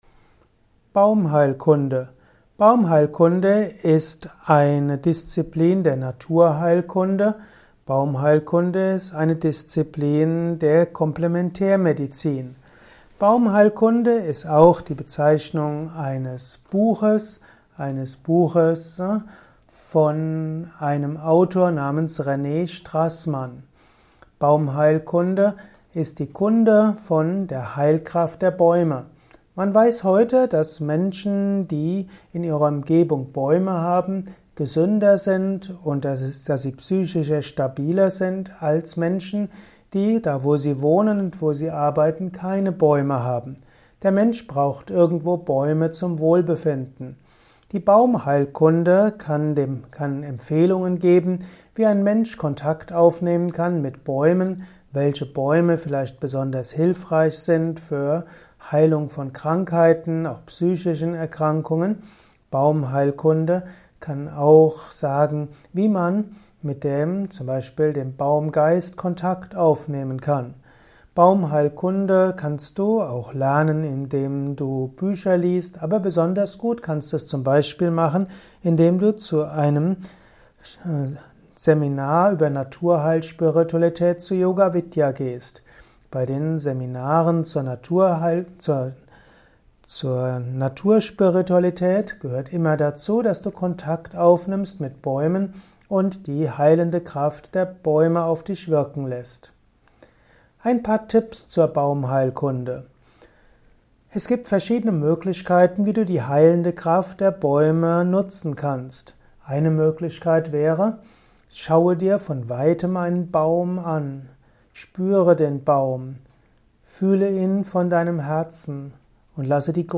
Kurzer Vortrag mit einigen interessanten Einsichten zu Baumheilkunde. Höre einiges über Baumheilkunde in diesem kurzen Vortragsaudio.